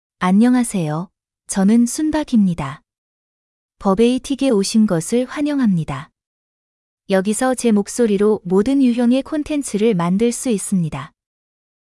SoonBok — Female Korean AI voice
SoonBok is a female AI voice for Korean (Korea).
Voice sample
Female
SoonBok delivers clear pronunciation with authentic Korea Korean intonation, making your content sound professionally produced.